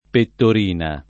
Pettorina [ pettor & na ] top. f. (Ven.)